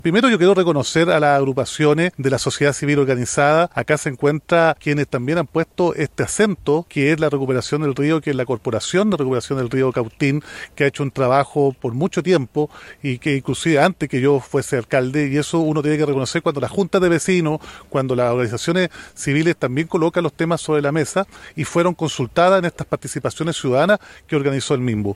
El interés demostrado y el trabajo desplegado por la propia ciudadanía organizada, que ha sostenido por años esta iniciativa, fue destacado por el alcalde de Temuco, Roberto Neira, quien se manifestó confiado en que pese al cambio de gobierno, se entienda que este es un proyecto de Estado.
La presentación del avance del proyecto se efectuó al interior del parque Langdon, esto en el sector oriente de Temuco.